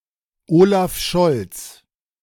Olaf Scholz (German: [ˈoːlaf ˈʃɔlts]
De-Olaf_Scholz.ogg.mp3